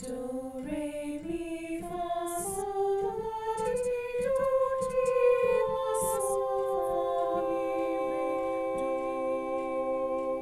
I ask them to sing a solfege scale ascending, and then on the descending scale ask my front row to stop and hold the high do, the middle row to stop on the sol, the back row to stop on mi, and I finish it off myself by singing down to the low do.
The ‘magic chord’ exercise
My choir calls this the “Magic Chord” warmup because of how the major triad chord seems to magically appear.
The-magic-chord-exercise.mp3